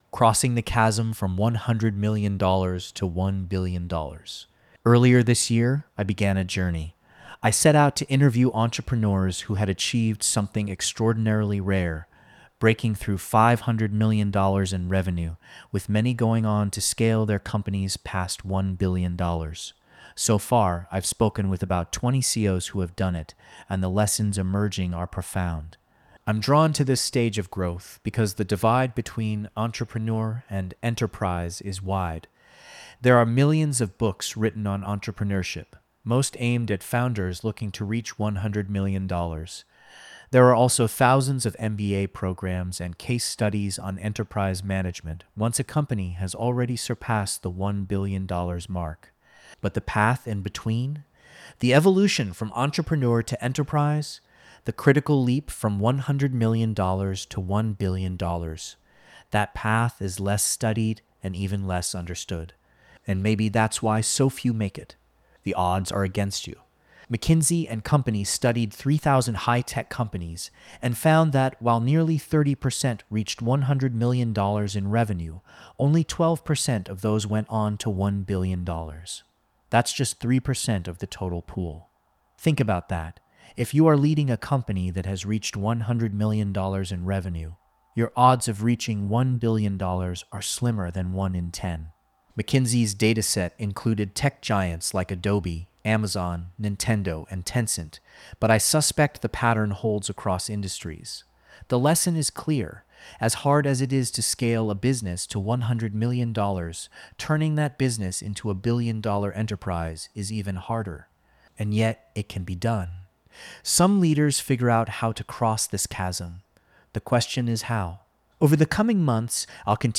This audio was recorded by AI.